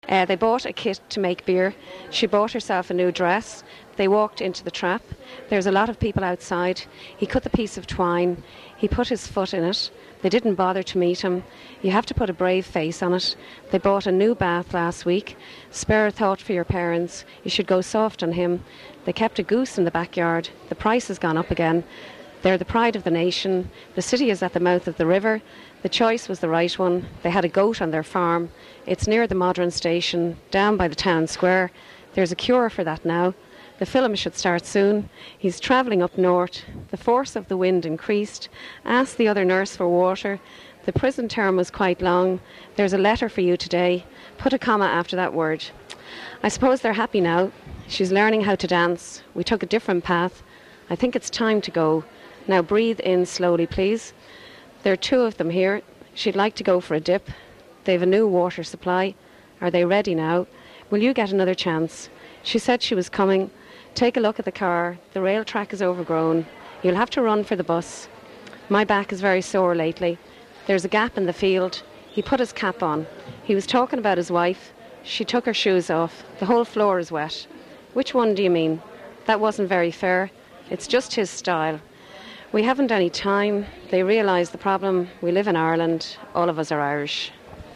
Local Dublin English (2 sound files)
DUB_Dundrum_F_40.wav